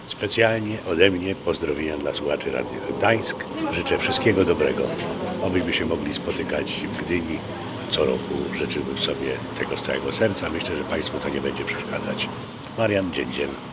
Spotkania z widzami traktuję jako swój obowiązek, mówi Radiu Gdańsk Marian Dziędziel.